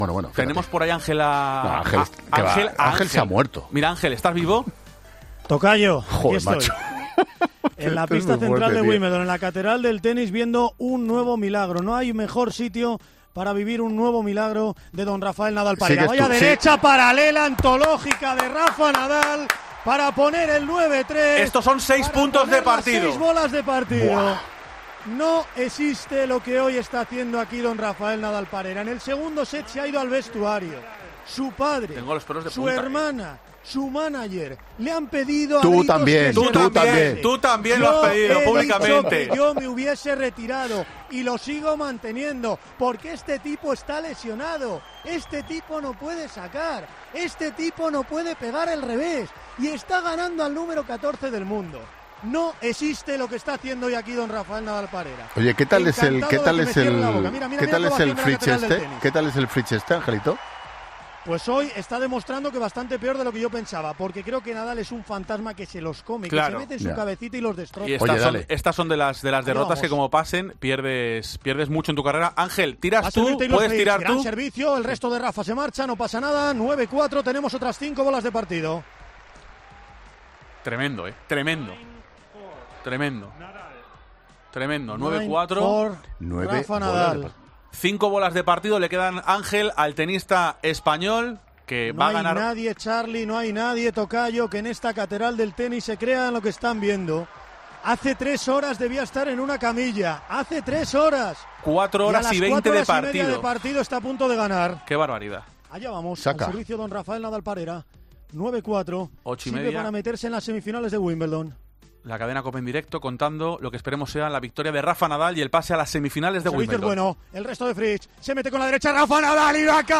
Así narramos en COPE el pase a semifinales de Rafa Nadal en Wimbledon